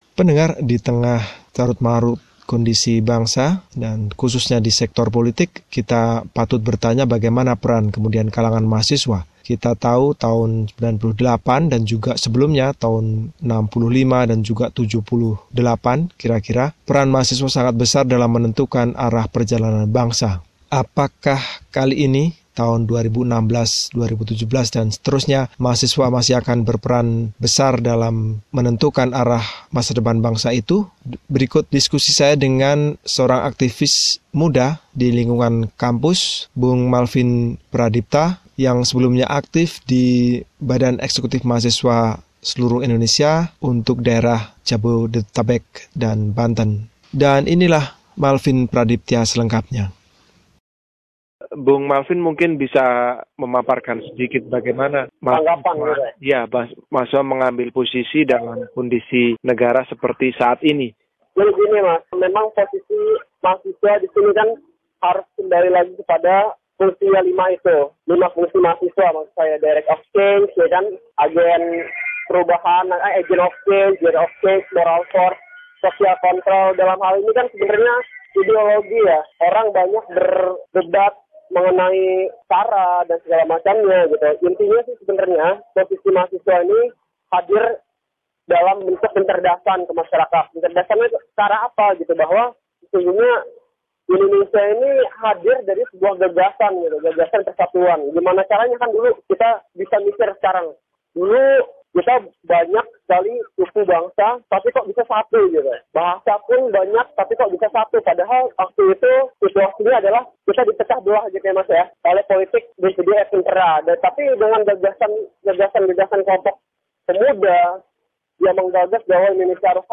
Wawancara